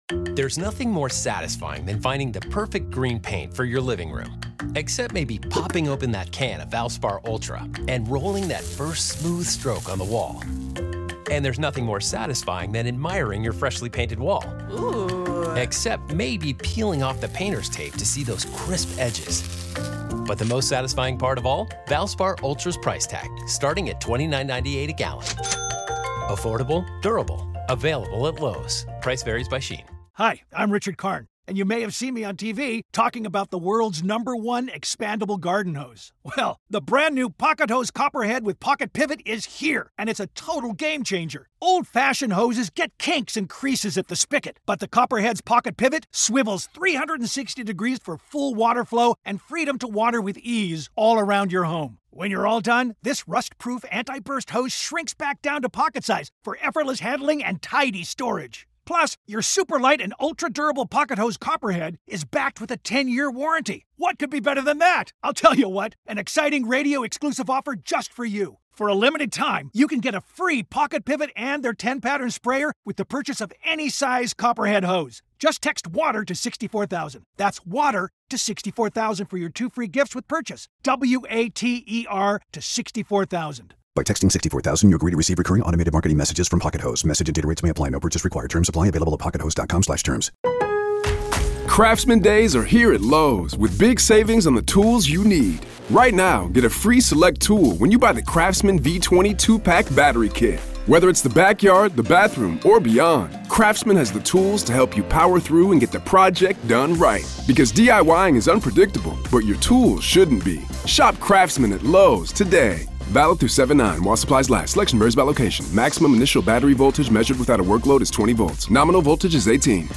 From missing taillight evidence and a body found in the snow, to tangled relationships between cops and witnesses inside the house, this conversation dissects the most controversial elements of the case. Is the defense taking on too much with theories they can’t prove?